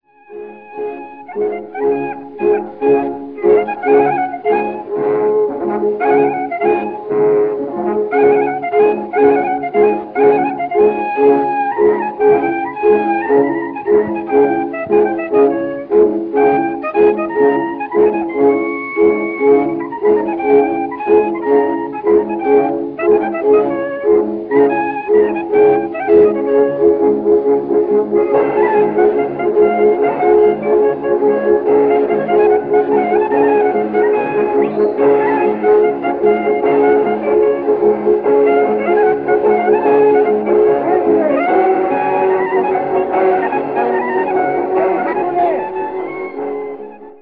Klezmer Music
Abe Schwartz and daughter Sylivia, c.1923From a slow and graceful movement Kallarash descends into Spike Jones territory, with manic slide whistle - or is it, as Schwartz maintains
play Sound Clip(on page 9) 'produced by blowing on a disc' - and cymbal crashing (sound clip).
Remastering of the original discs is generally good throughout.